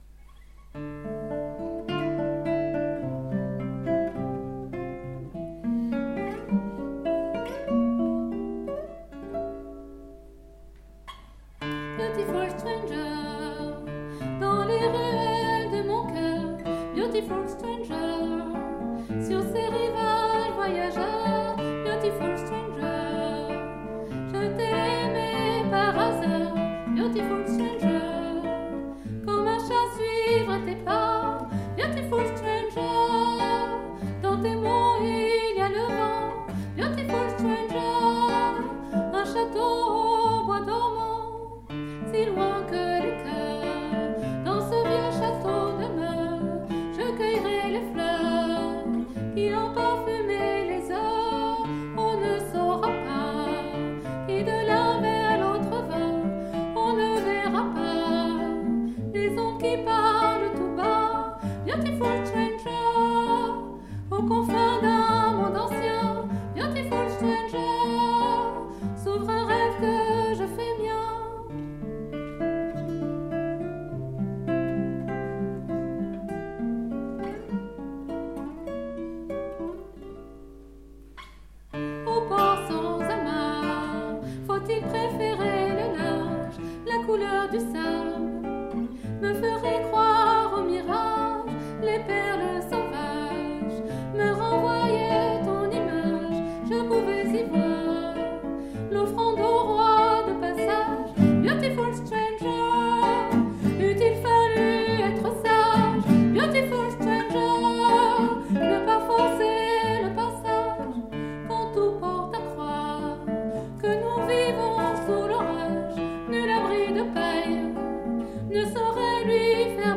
Beautyfull stanger. Composition et chant
guitare